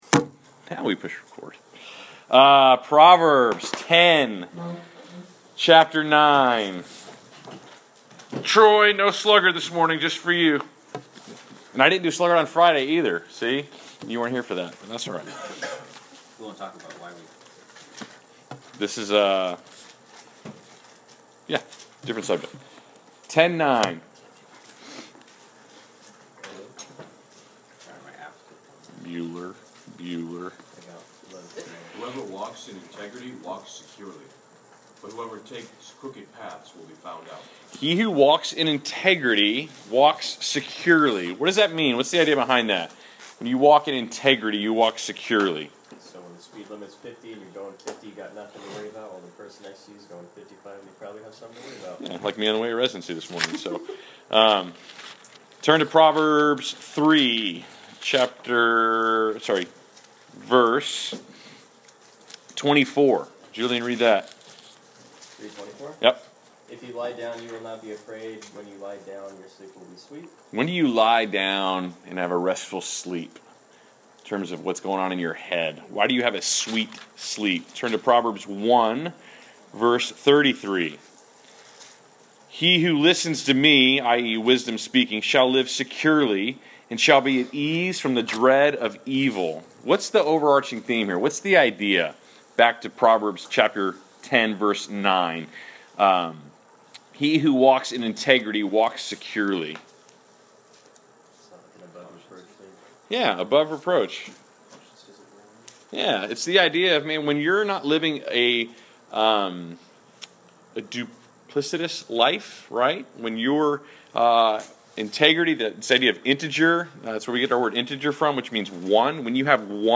Class Session Audio February 16